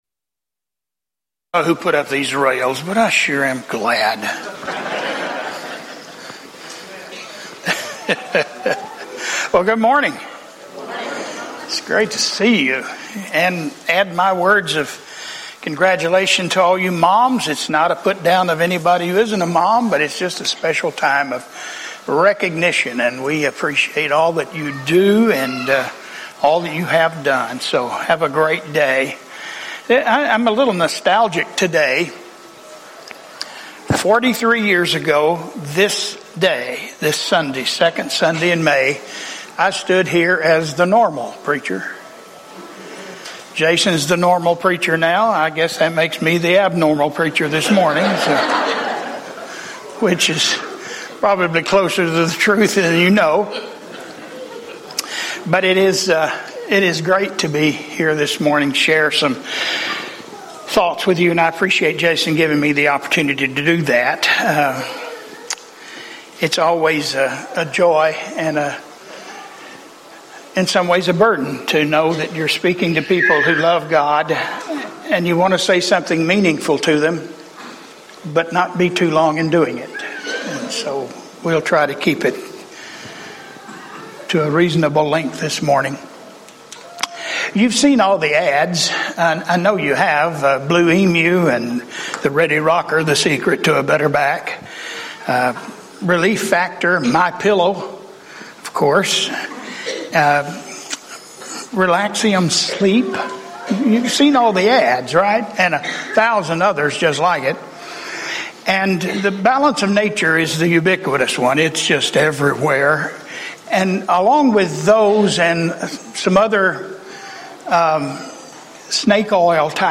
From Series: "English Sermons - 10:15"